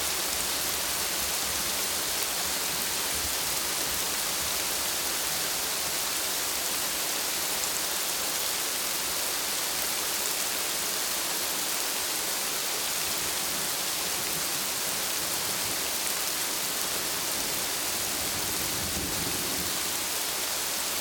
rain_loop.ogg